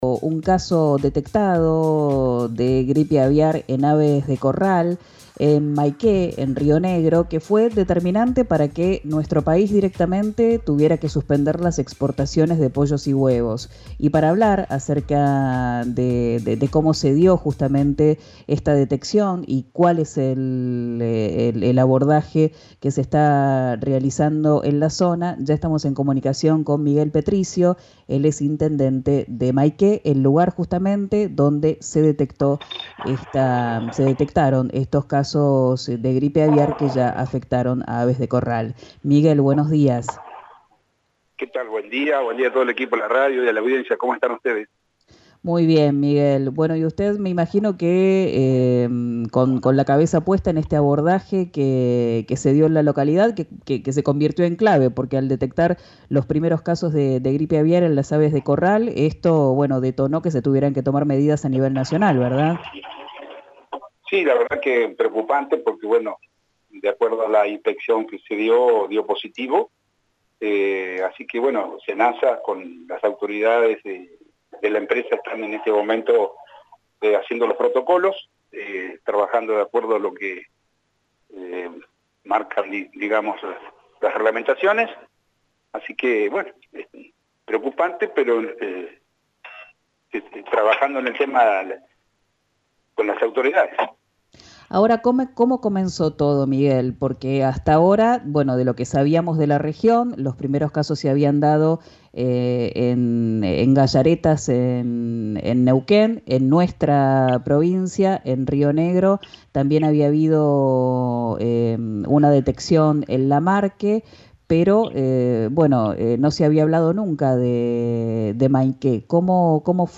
Escuchá a Miguel Petricio, intendente de Mainqué, en «Quien dijo verano» por RÍO NEGRO RADIO: